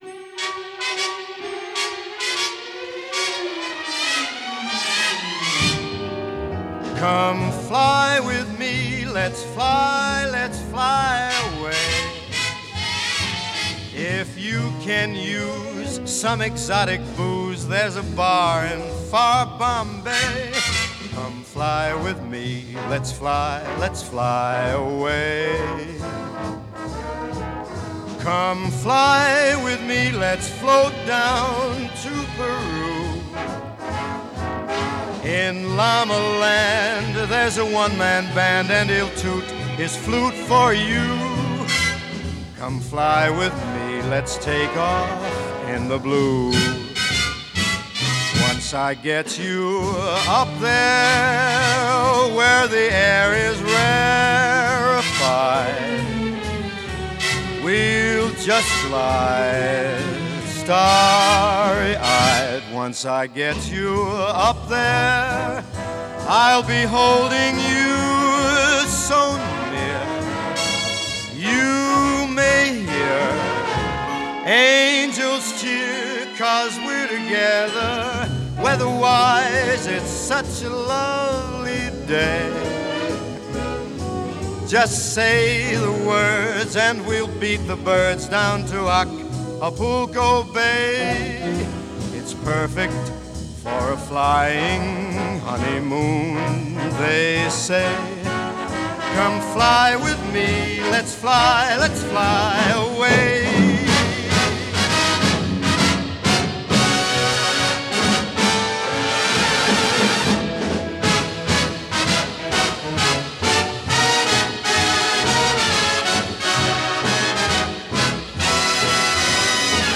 U47是20世纪时顶级歌手在录音棚时最常用的人声麦克风之一。